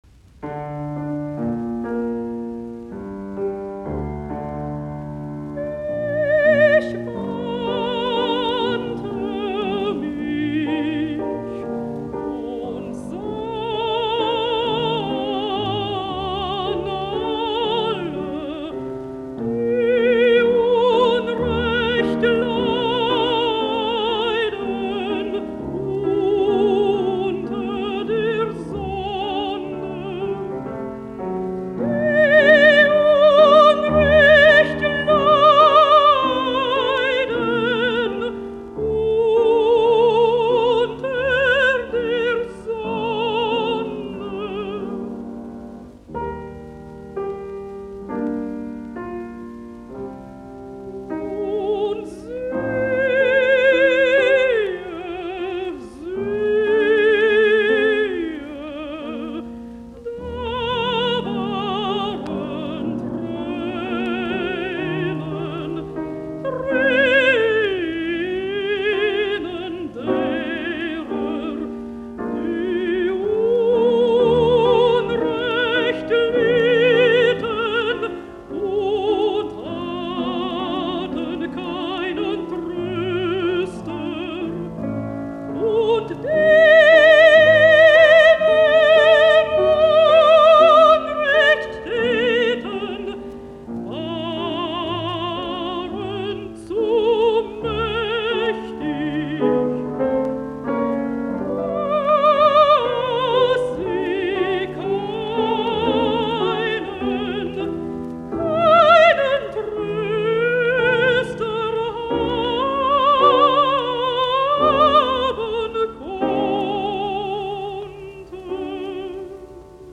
musiikkiäänite
Rankin, Nell ( altto ) Songs of Brahms.